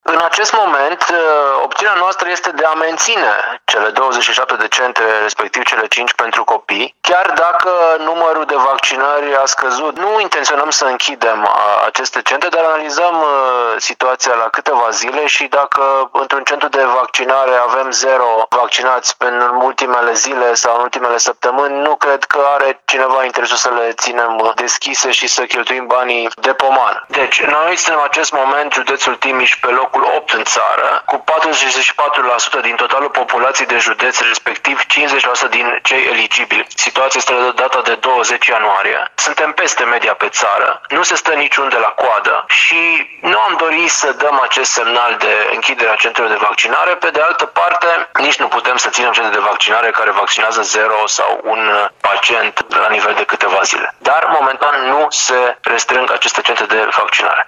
Deocamdată însă, toate cele 27 de centre de vaccinare în care funcționează și 5 cabinete pediatrice vor rămâne deschise spune prefectul județului Timiș, Mihai Ritivoiu.